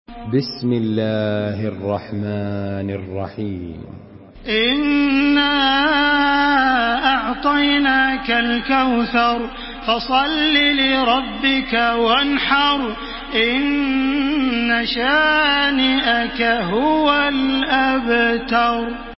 Surah আল-কাউসার MP3 by Makkah Taraweeh 1433 in Hafs An Asim narration.
Murattal